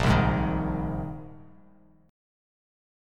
Bb7sus2sus4 chord